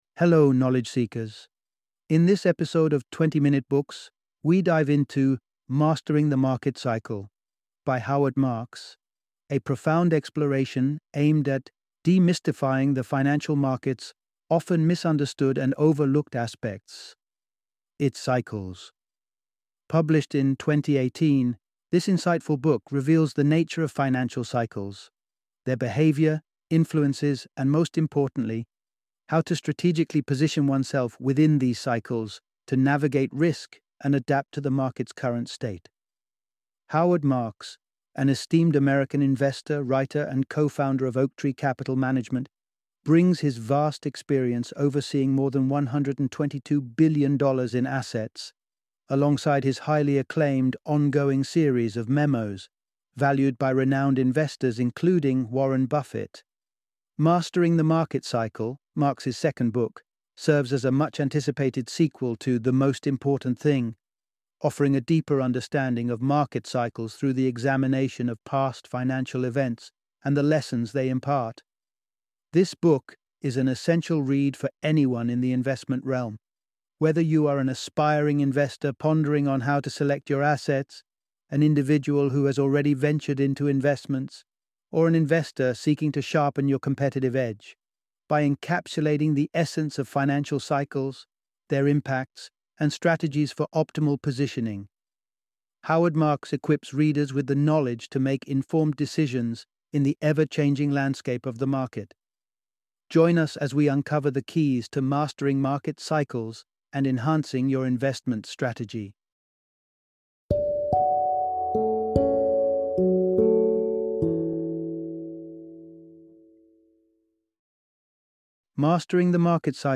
Mastering the Market Cycle - Audiobook Summary